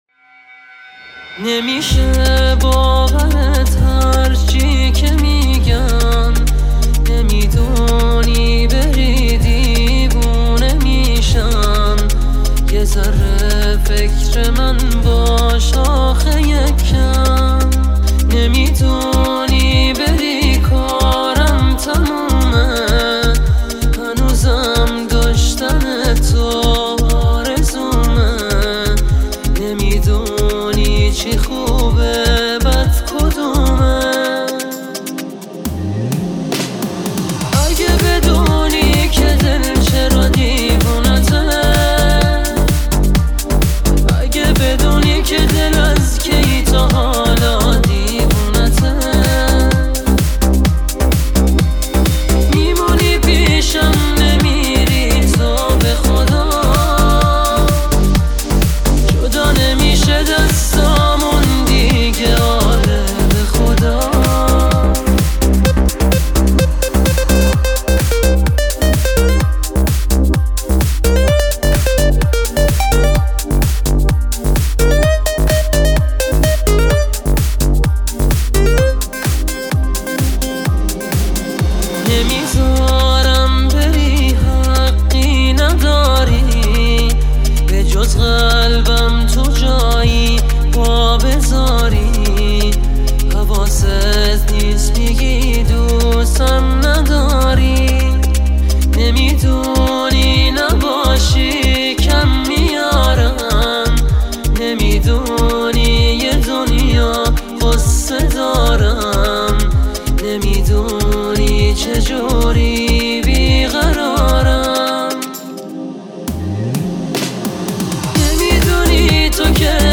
آهنگ جدید و غمگین